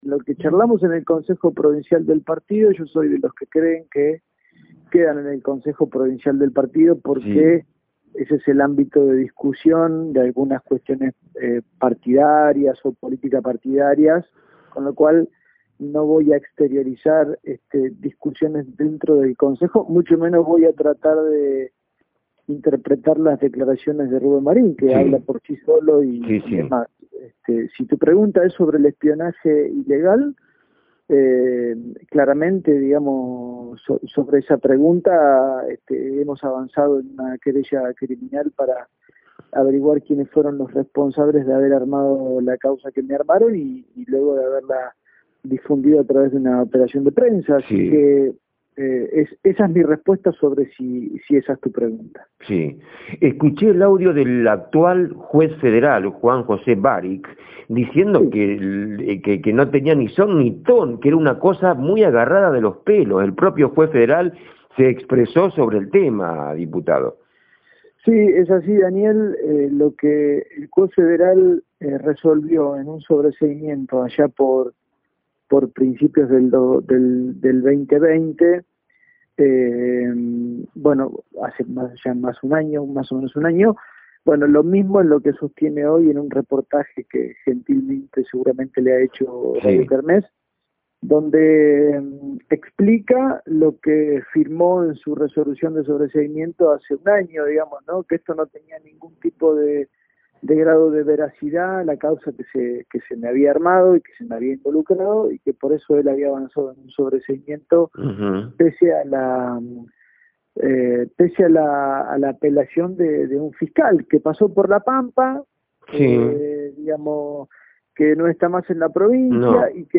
El diputado provincial Espartaco Marin, confirmó en LU100 Radio Capital AM 1040 FM 102.5, que hoy lunes 22 de marzo comienzan a declarar sobre el armado de su causa, lo cual lo involucraba en una causa de narcotráfico. "Se ha avanzado en la investigación dobre el armado de la causa", explicó el diputado, quien contó en exclusiva que "es probable" que hoy lunes "declare el Juez Padilla".